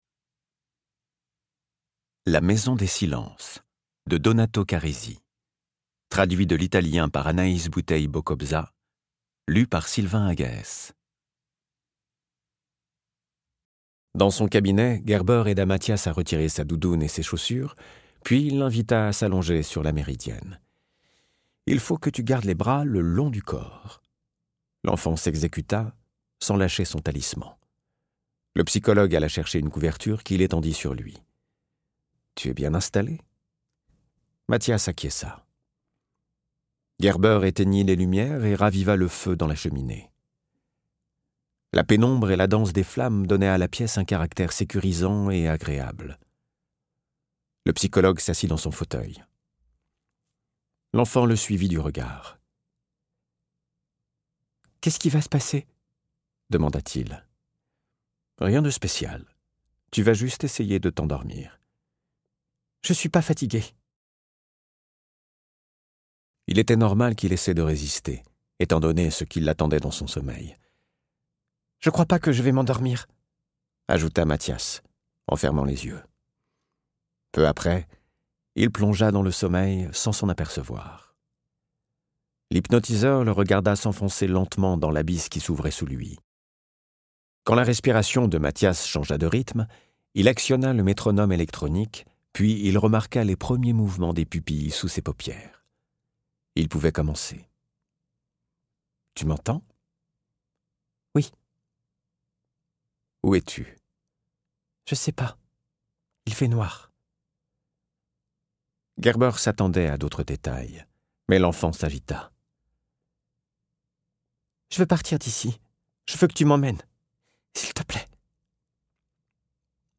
Dans ce nouveau thriller psychologique, Donato Carrisi nous plonge dans un labyrinthe de dangereux faux semblants. Interprétation humaine Durée : 08H07 × Guide des formats Les livres numériques peuvent être téléchargés depuis l'ebookstore Numilog ou directement depuis une tablette ou smartphone.